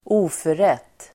Ladda ner uttalet
Uttal: [²'o:föret:]